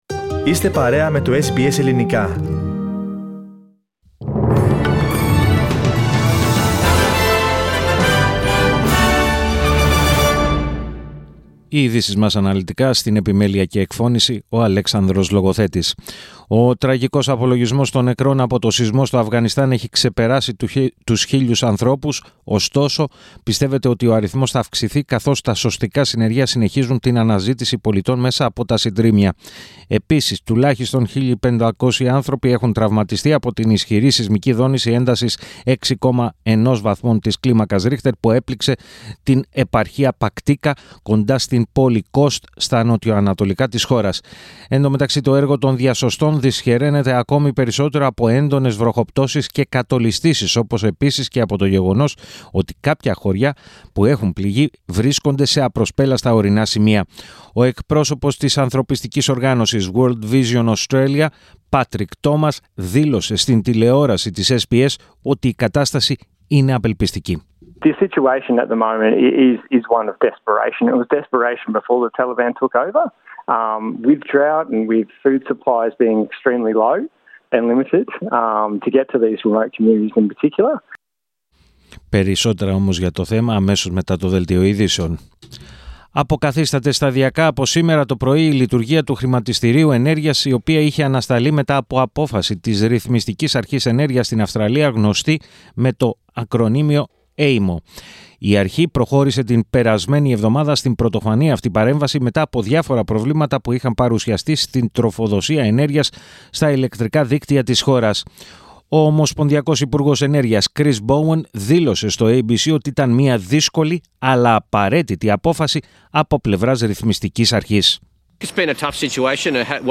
Το αναλυτικό δελτίο ειδήσεων του Ελληνικού Προγράμματος της ραδιοφωνίας SBS, στις 16:00.